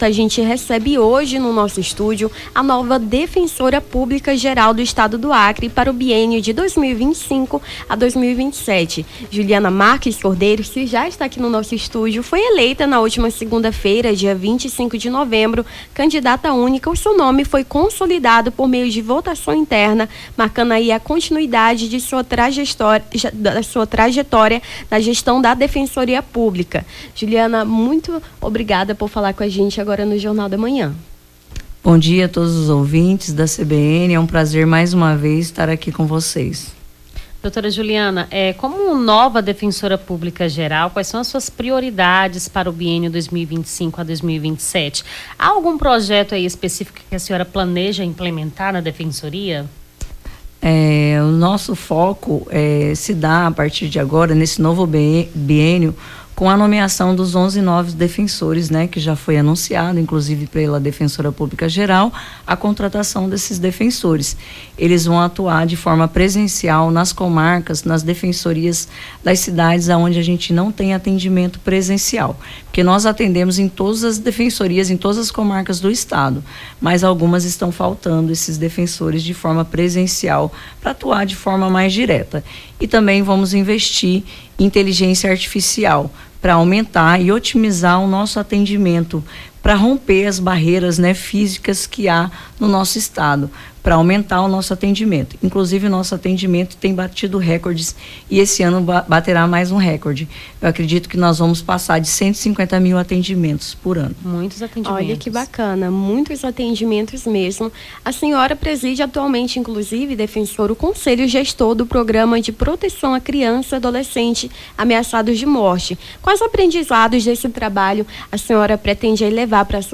Nome do Artista - CENSURA - ENTREVISTA DEFENSORIA PÚBLICA GERAL DO ACRE (28-11-24).mp3